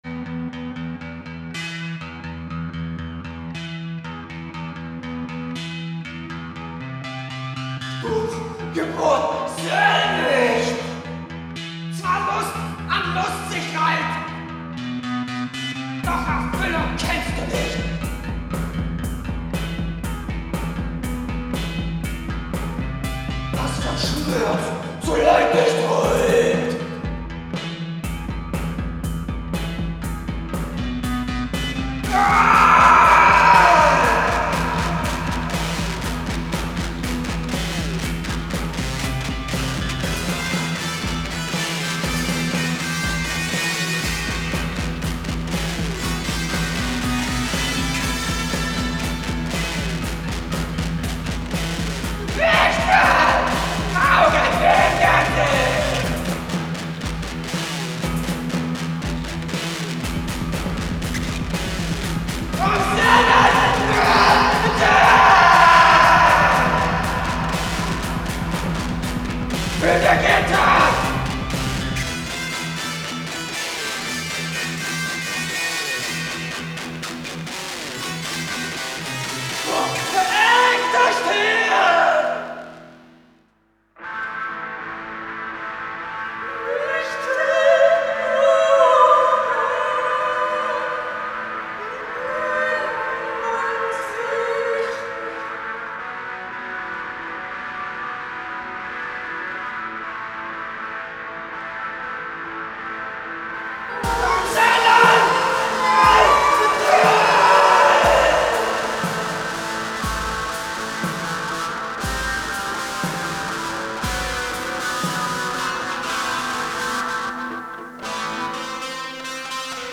Super basse disco, super fête, super ambiance